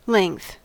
Ääntäminen
Ääntäminen US : IPA : [lɛŋθ]